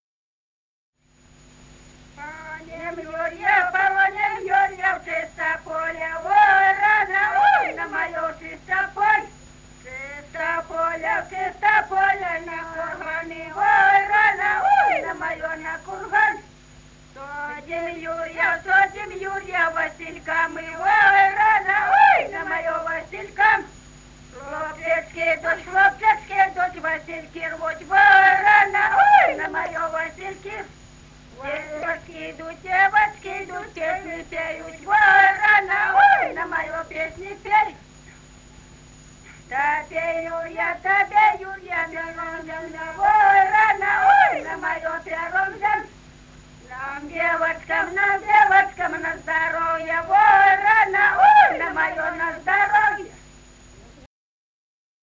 Народные песни Стародубского района «Погоним Юрья», юрьевская таночная.
1959 г., с. Курковичи.